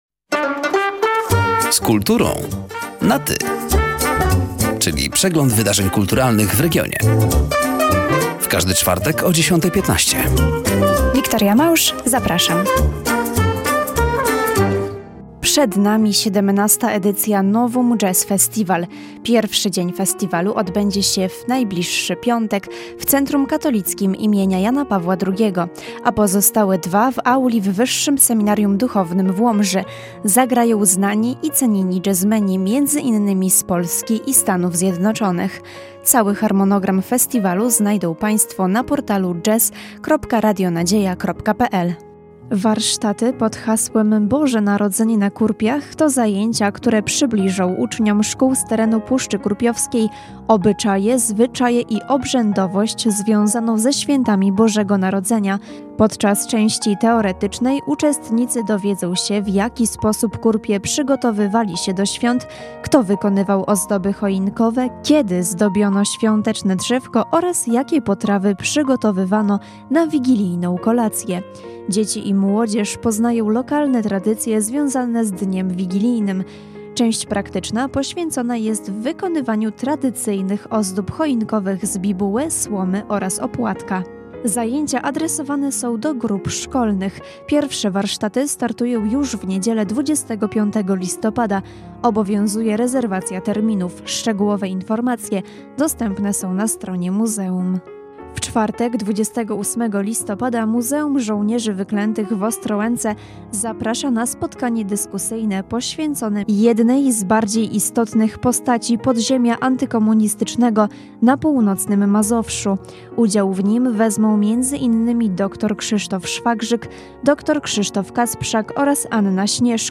Zapraszamy do odsłuchania rozmowy i zapoznania się ze zbliżającymi wydarzeniami kulturalnymi w regionie:
,,Z kulturą na Ty” na antenie Radia Nadzieja w każdy czwartek o 10.15.